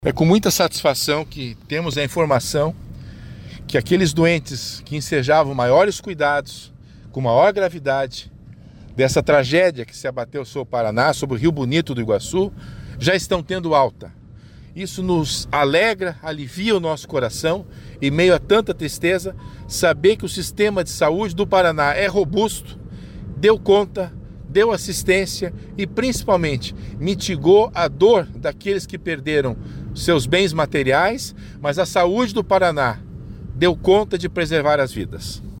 Sonora do secretário da Saúde em exercício, César Neves, sobre as altas hospitalares das vítimas dos tornados no Paraná